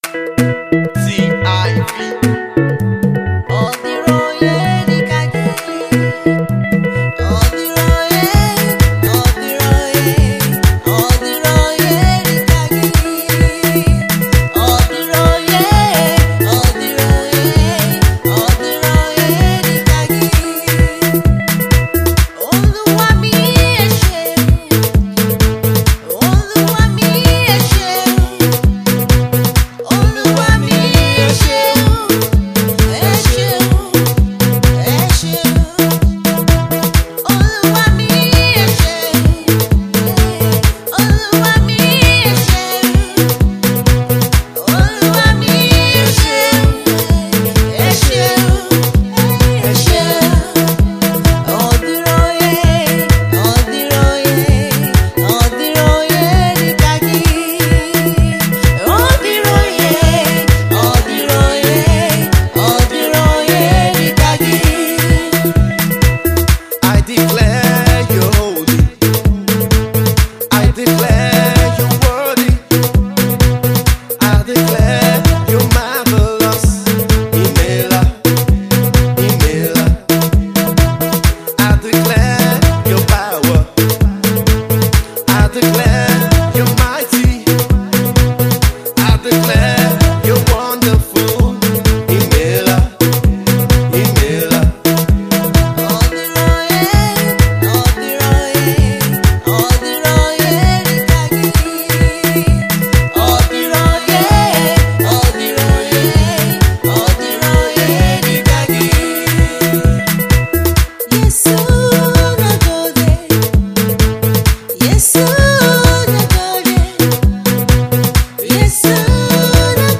praise song